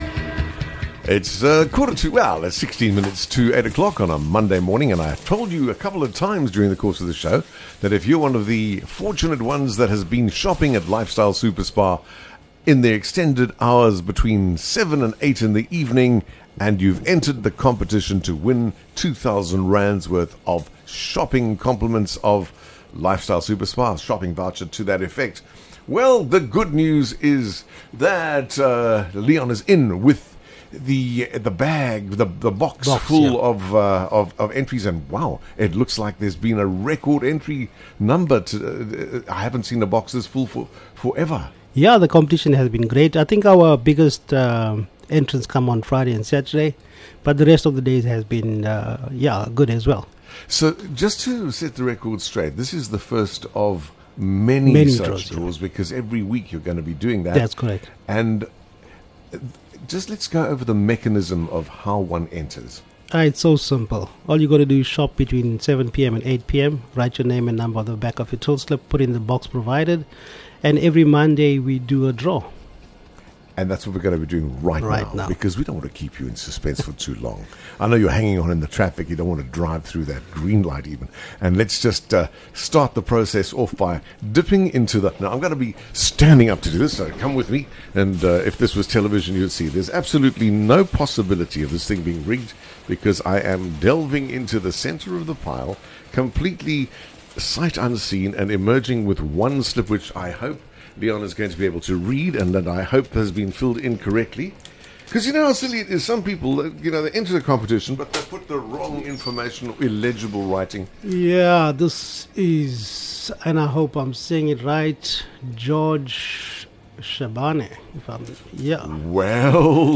Arrive between 7:00 PM and 8:00 PM and stand a chance to enter and win a R2000 shopping voucher! Listen to our interview to learn how to enter and what is required.